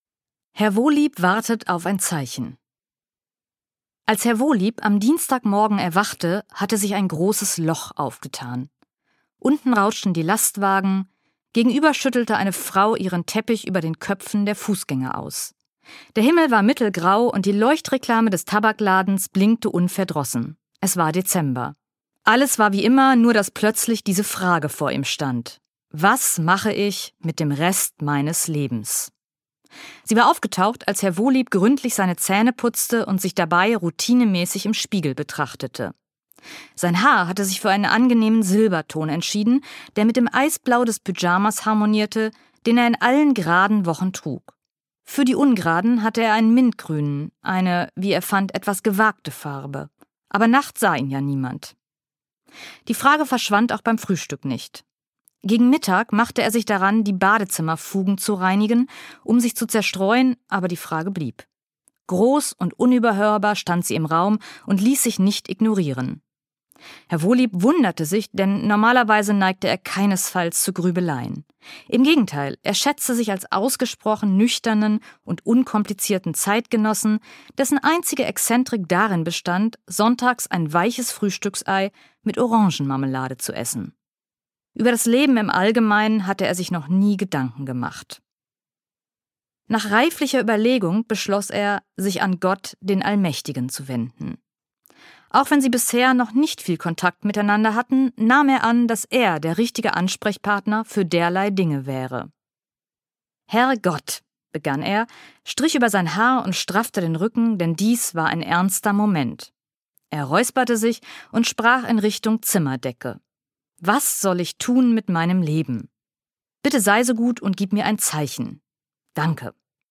Schlagworte Advent • Hörbuch • Hörbuch; Literaturlesung • Spiritualität • Weihnachten • Weihnachtsgeschichten / Weihnachtserzählungen • Weihnachtszeit